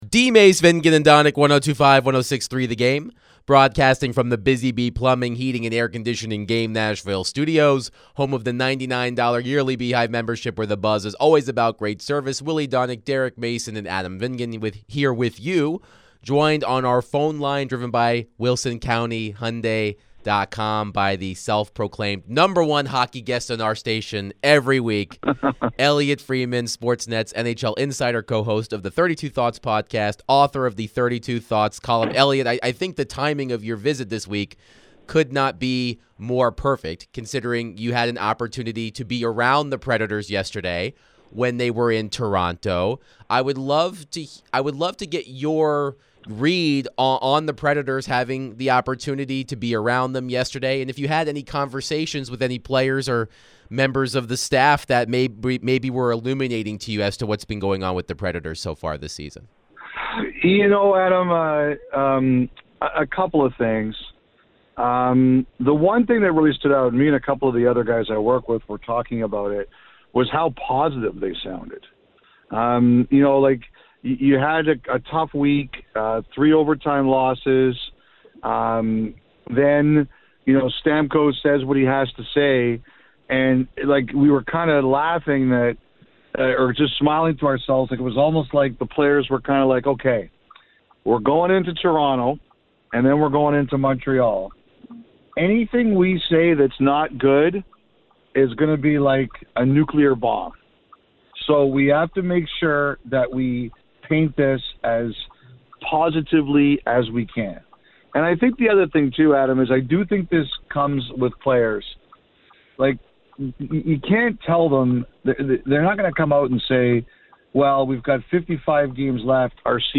NHL Insider Elliotte Friedman joined the show to discuss the Preds' five-game skid. What does Elliotte think is the problem for the Predators right now?